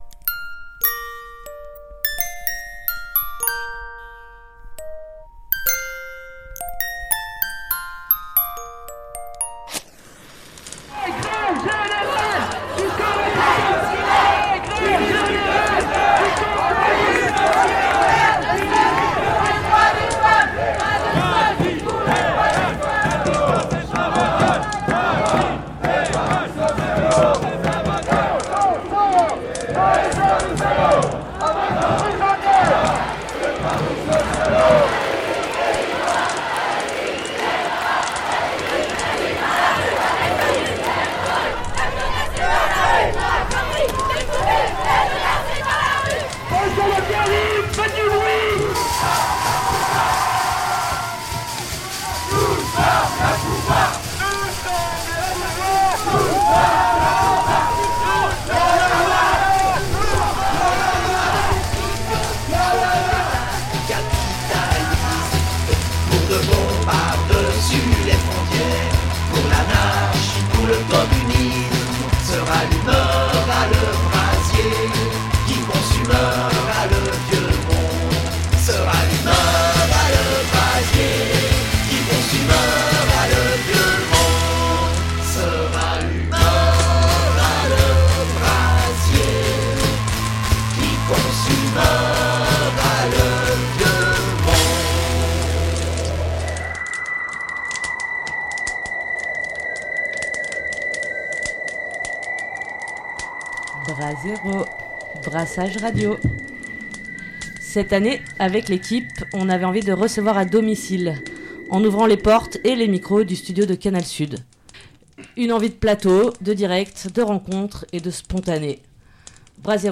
Cette année, avec l’équipe, on avait envie de recevoir à domicile, en ouvrant les portes et les micros du studio de canal sud.
Brasero 2024-2025 c’est plus de radio et plus de rendez-vous les lundi de 19h à 20h en direct.